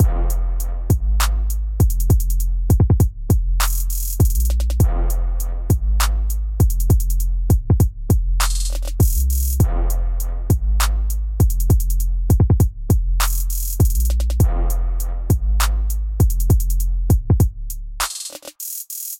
陷阱鼓808和铜管
描述：ums和铜管乐器完美地用于音阶小调的陷阱节拍。
Tag: 100 bpm Trap Loops Drum Loops 3.23 MB wav Key : F